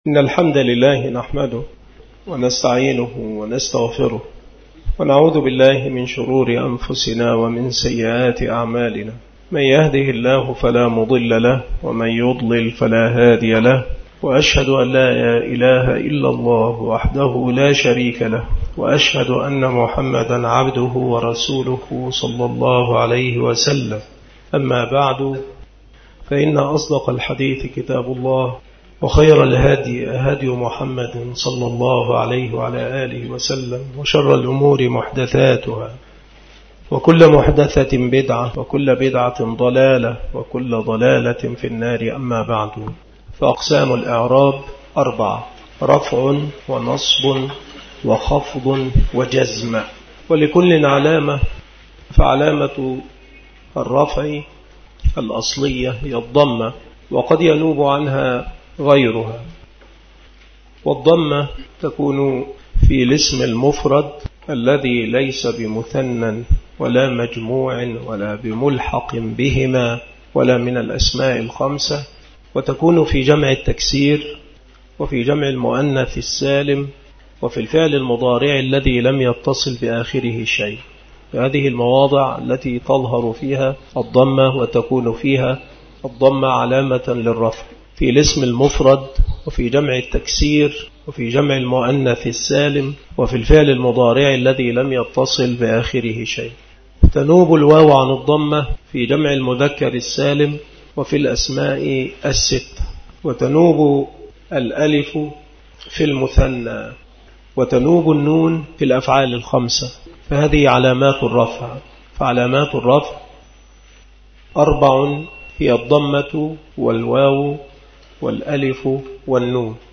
مكان إلقاء هذه المحاضرة بالمسجد الشرقي بسبك الأحد - أشمون - محافظة المنوفية - مصر عناصر المحاضرة : أقسام الإعراب أربعة. علامات الخفض. الكسرة ومواضعها. نيابة الياء عن الكسرة. نيابة الفتحة عن الكسرة.